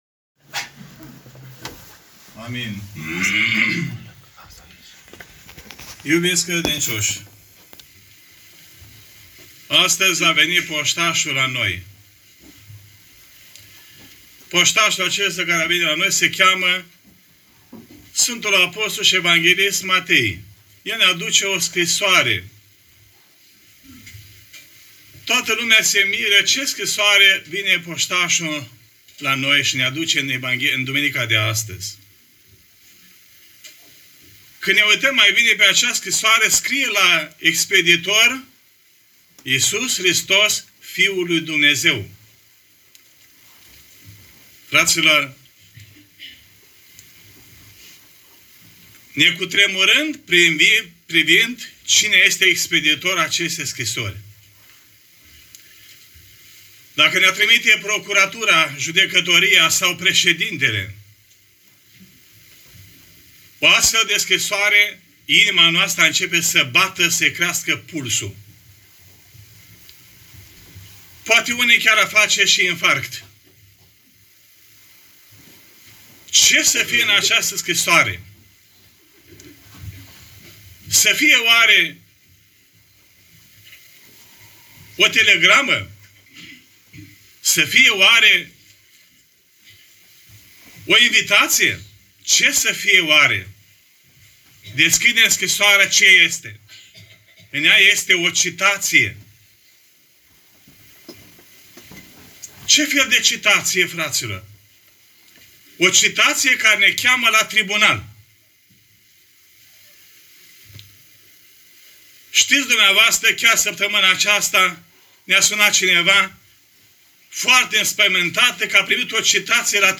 Predica poate fi descărcată în format audio mp3 de aici: